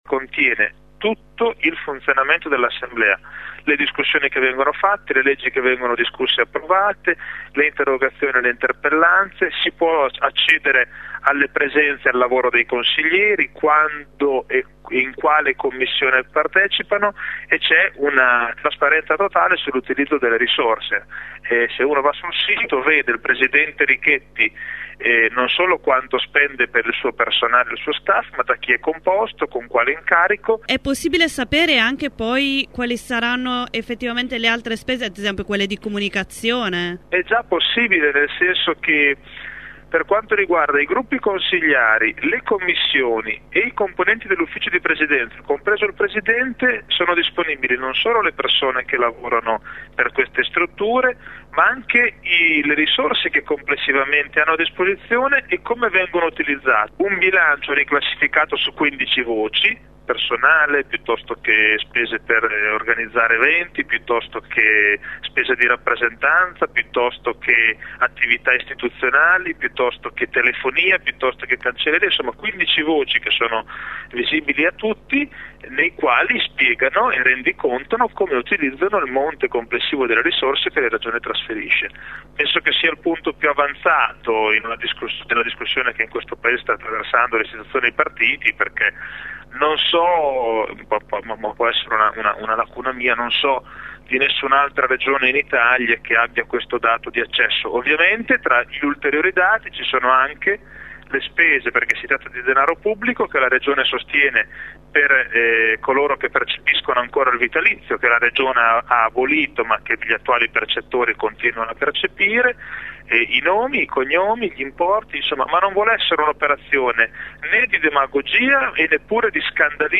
Quali dati si possono trovare l’abbiamo chiesto al presidente dell’assemblea legislativa Matteo Richetti